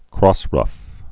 (krôsrŭf, -rŭf, krŏs-)